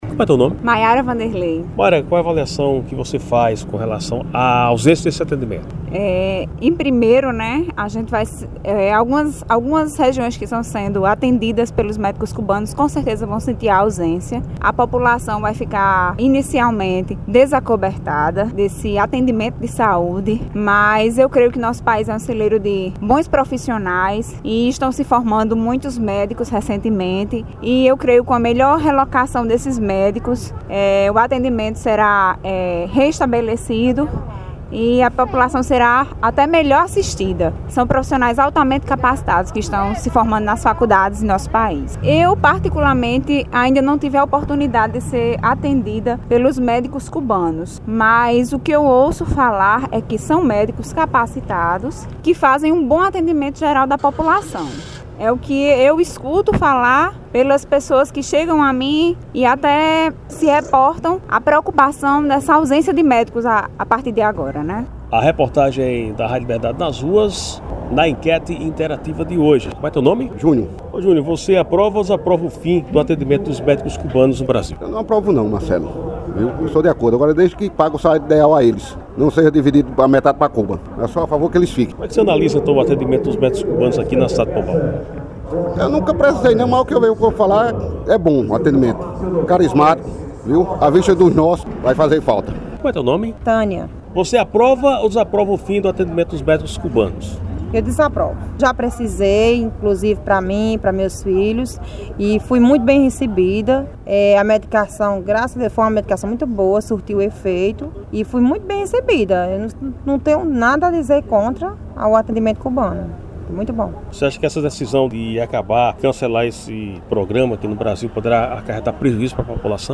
Com o posicionamento adotado pelo governo cubano de retirar profissionais do programa “Mais Médicos”, a reportagem da Rádio Liberdade FM foi às ruas para ouvir a opinião das pessoas.